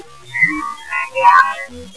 The following EVP ghost - spirit files are from that recording session.
This whispery but loud spirit voice clearly states his full name.